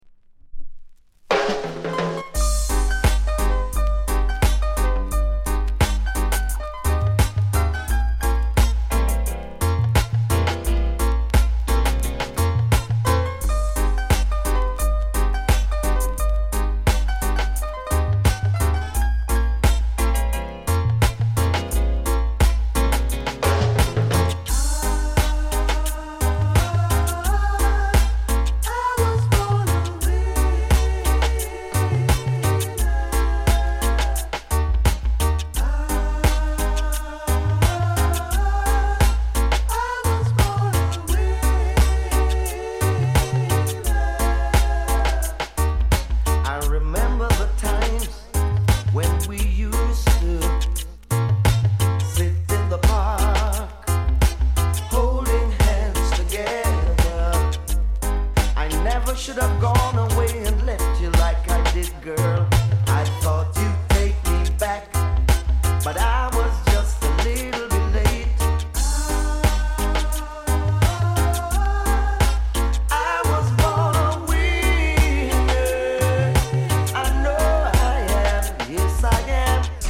類別 雷鬼